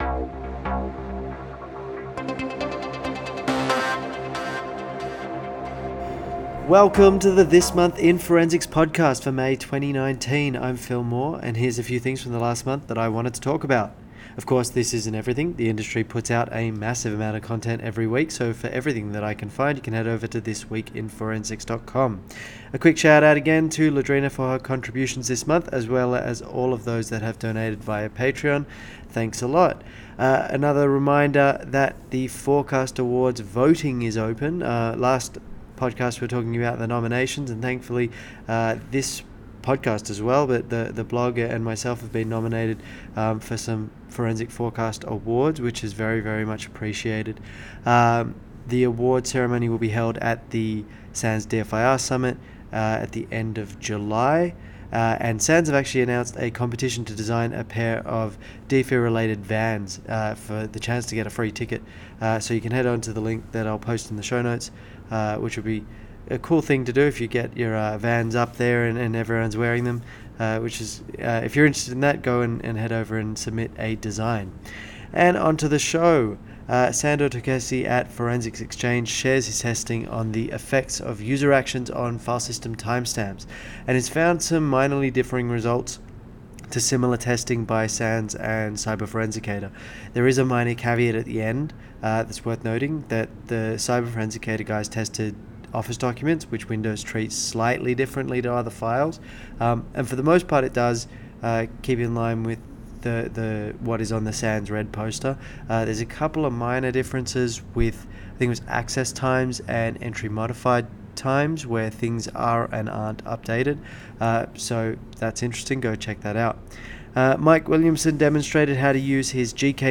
*Apologies for the name pronunciations, ums and ahs, and general production quality 🙂